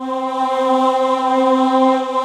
Index of /90_sSampleCDs/AKAI S6000 CD-ROM - Volume 1/VOCAL_ORGAN/BIG_CHOIR
CHOIR-4   -S.WAV